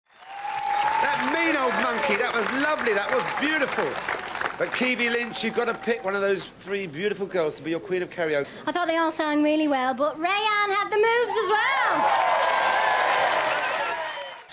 With ABBA tribute band, Bjorn Again.
Keavy never sang solo or as a duet, but apart from the typical "yay!" sound that she makes, she only spoke once.